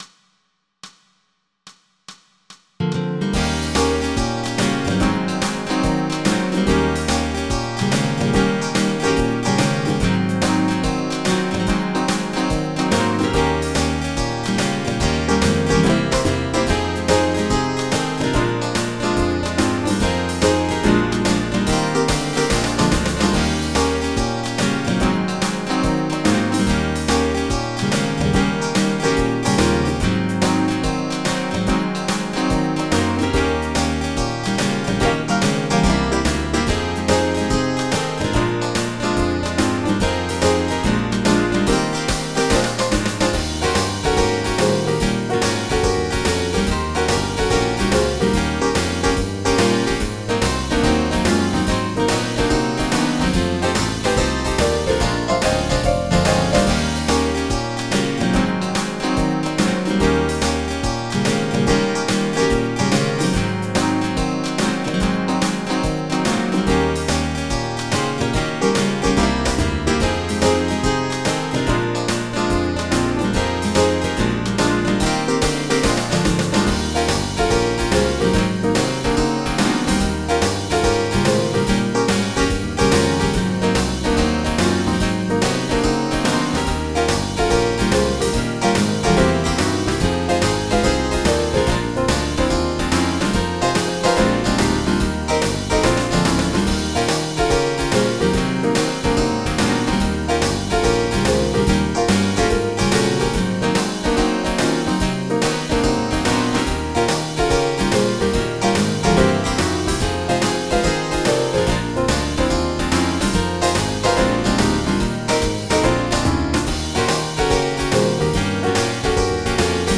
Type General MIDI